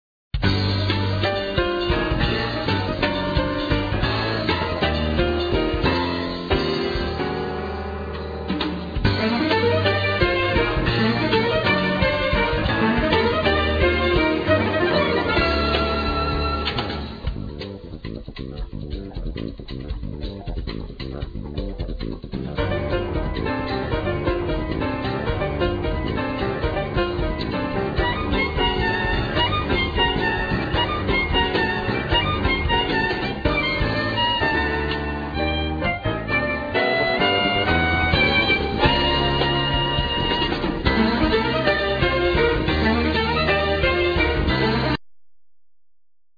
Violin
Piano,Keyboards
Bass
Drums,Percussions